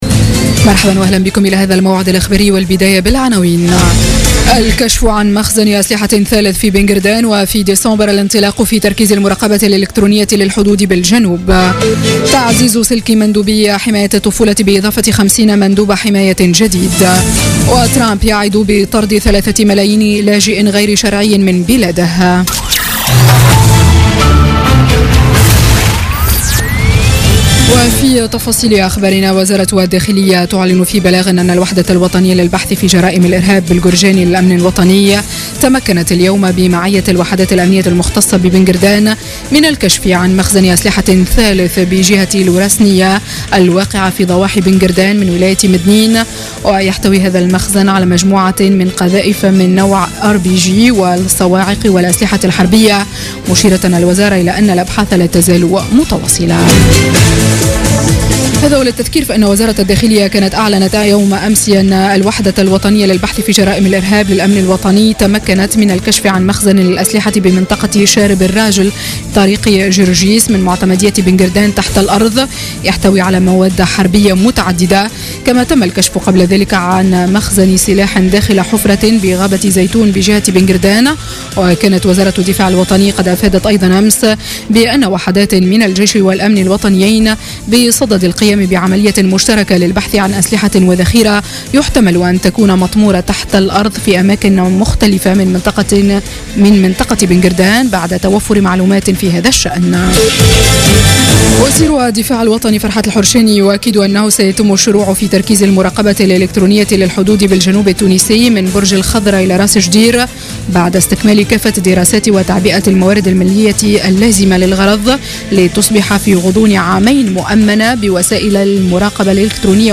Journal Info 19h00 du dimanche 13 novembre 2016